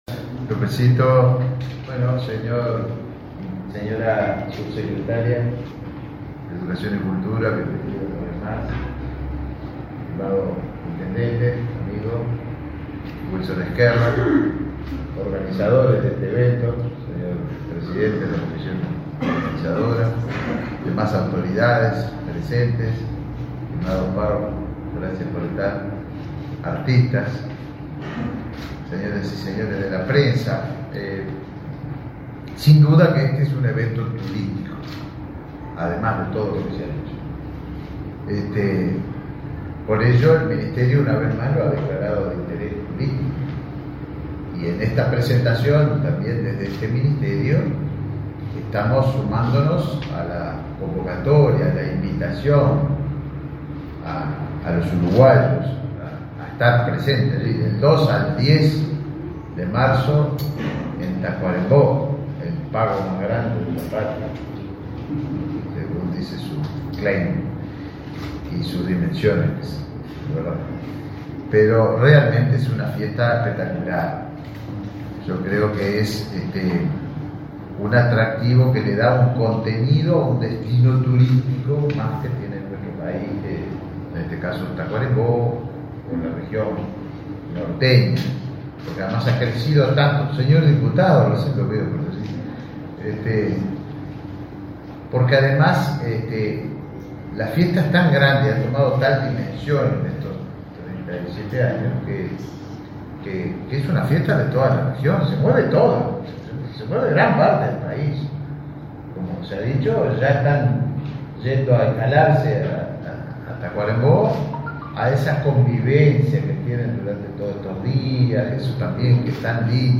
Acto de lanzamiento de la Fiesta de la Patria Gaucha
Participaron del evento el ministro Tabaré Viera y el intendente Wilson Ezquerra.